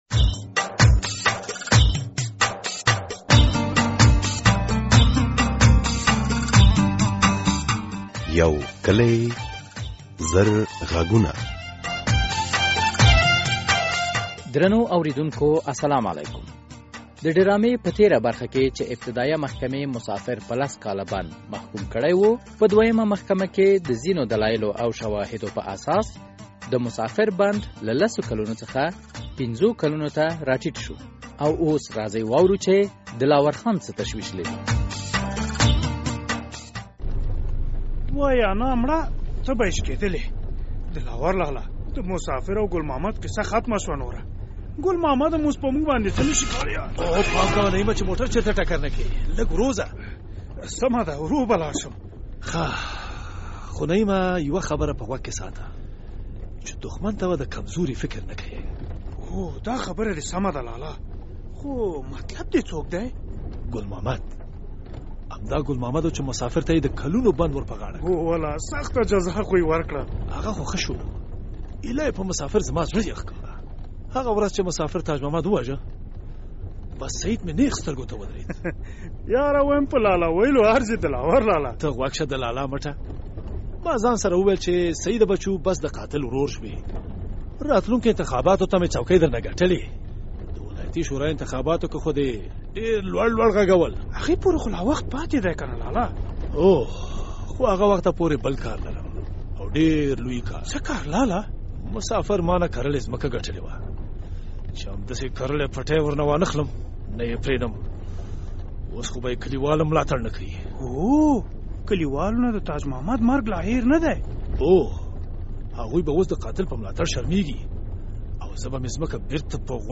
یو کلي او زرغږونه ډرامه هره اوونۍ د دوشنبې په ورځ څلور نیمې بجې له ازادي راډیو خپریږي.